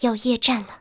T-80夜战语音.OGG